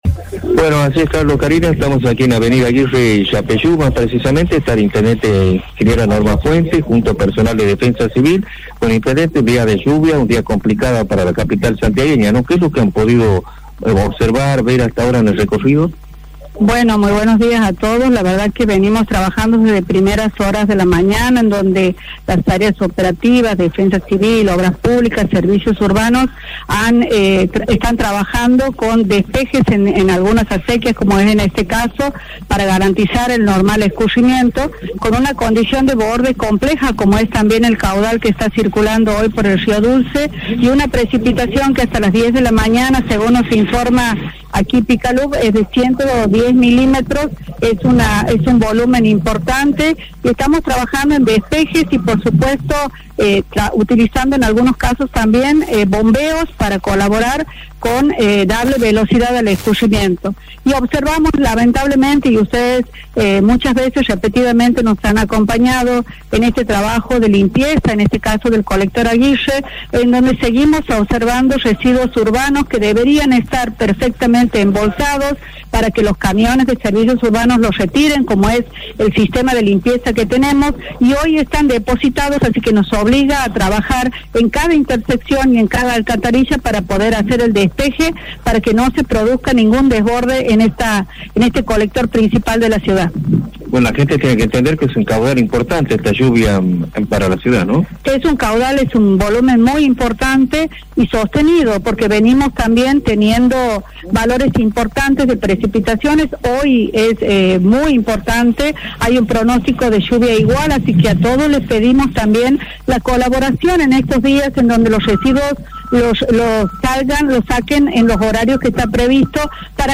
Fuentes habló por el diluvio en la Capital: «La Municipalidad está haciendo un enorme trabajo». Así lo expresó en diálogo con Radio Panorama la intendenta Norma Fuentes tras las intensas precipitaciones que se registran desde la madrugada en Santiago del Estero.